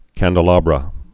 (kăndl-äbrə, -ăbrə, -ābrə)